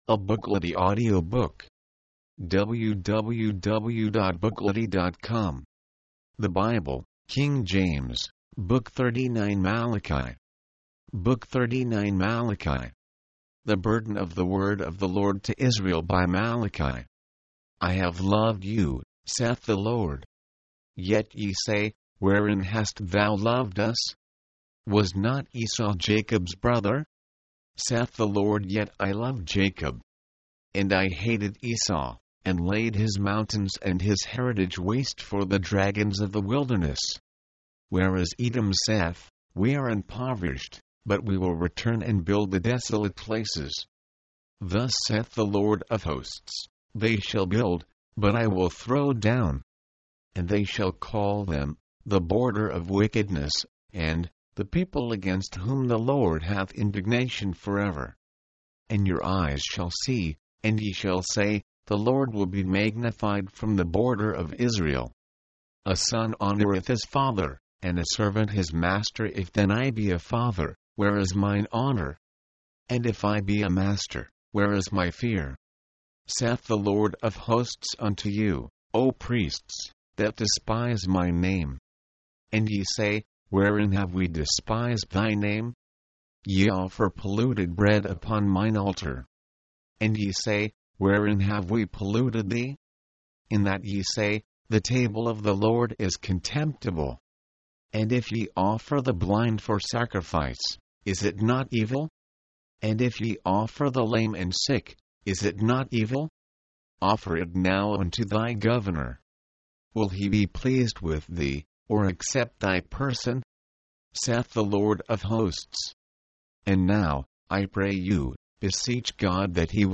Malachi tried to stir them up by preaching about the day of the Lord. mp3, audiobook, audio, book Date Added: Dec/31/1969 Rating: Add your review